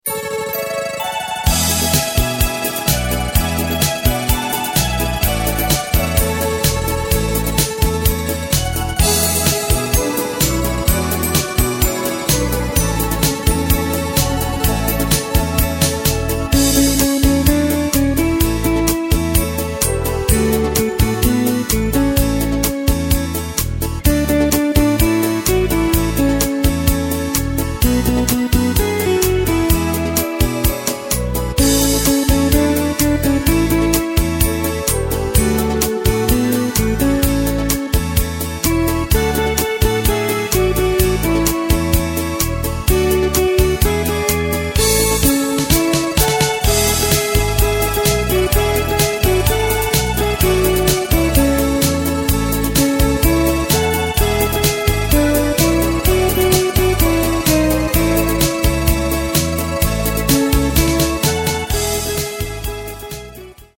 Takt:          4/4
Tempo:         127.50
Tonart:            A
Schlager aus dem Jahr 1991!